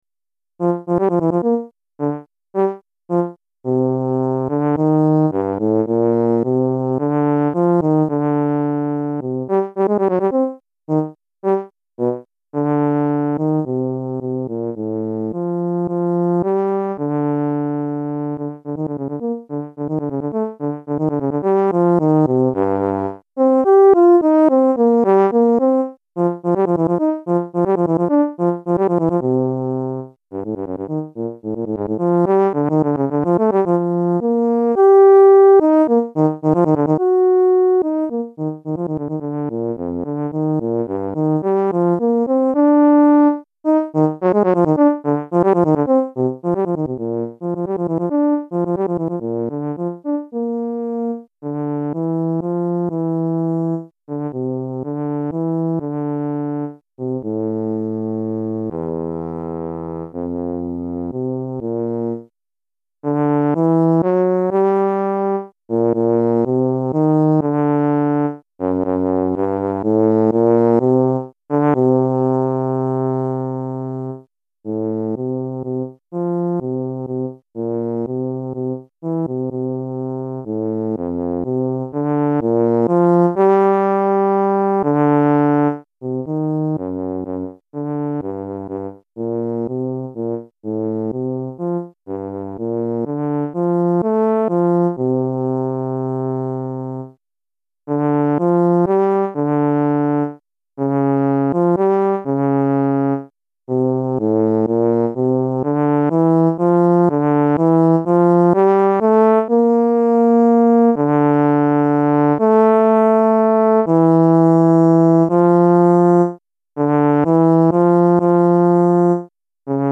Euphonium ou Saxhorn Solo